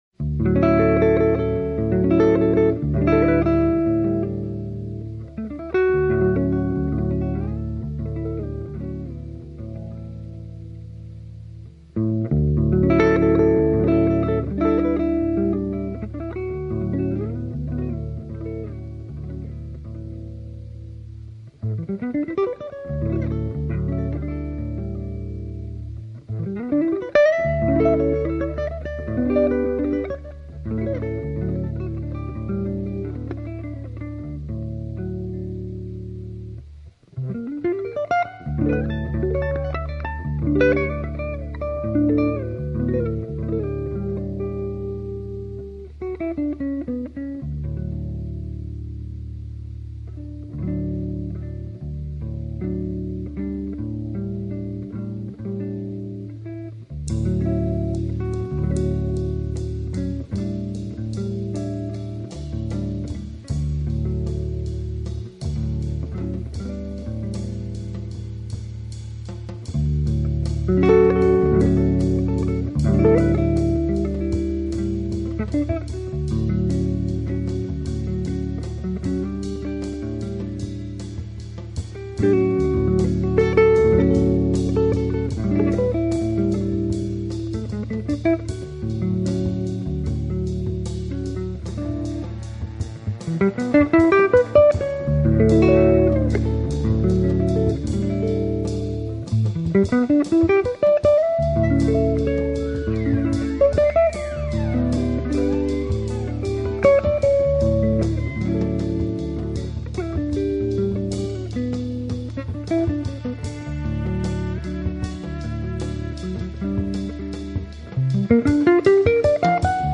Genre: Jazz / Contemporary Jazz